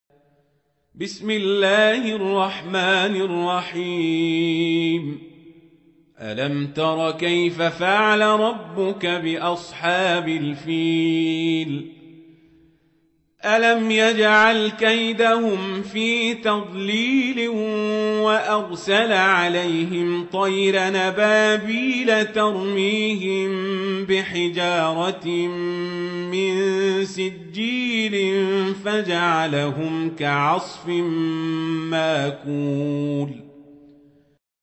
سورة الفيل | القارئ عمر القزابري